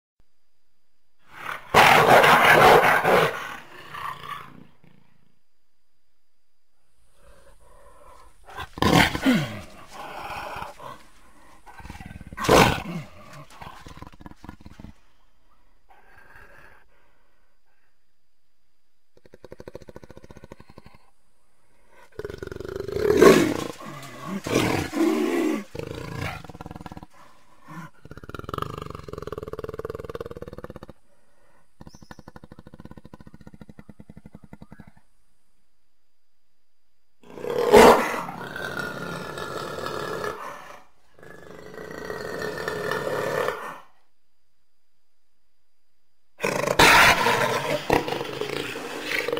Jaguarroaring.mp3